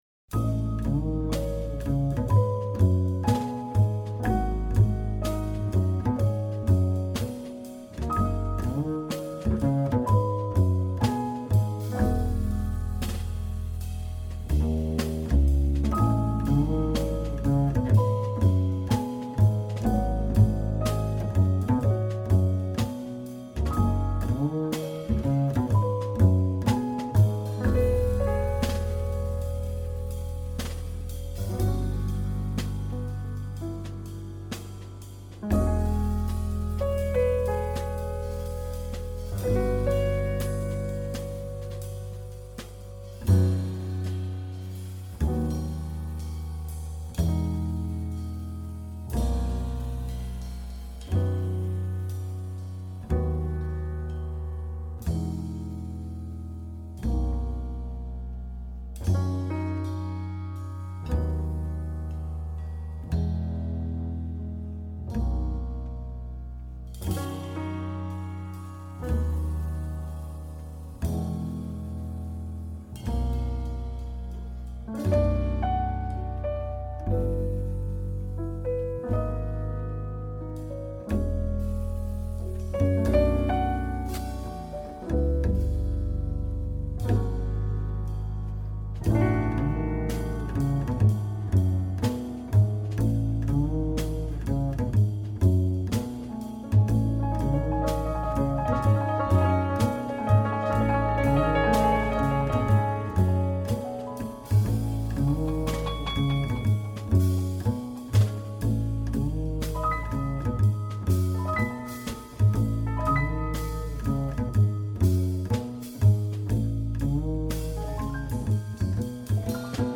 experimental jazz trio
2. The piano, lazily plunking out its notes.
Tags1990s 1993 Eastern US Jazz